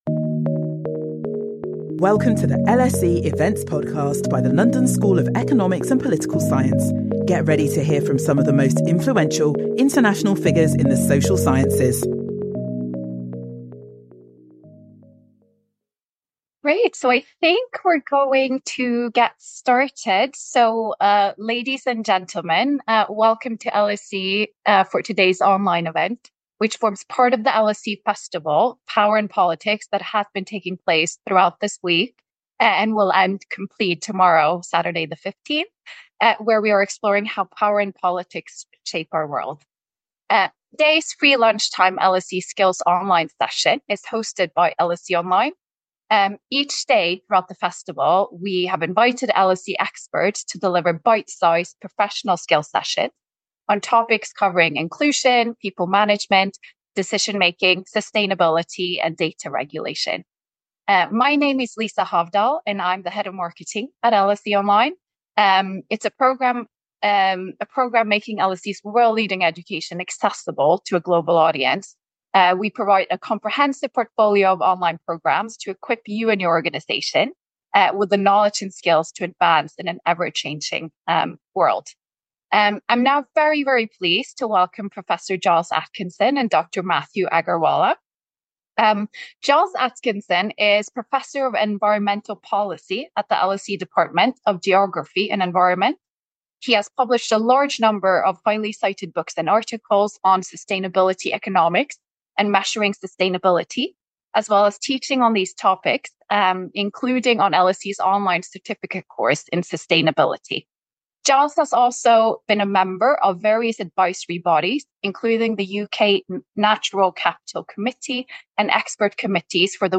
Our panel explore how we can go “Beyond GDP” to measure both the economic progress of nations and the sustainability of the planet?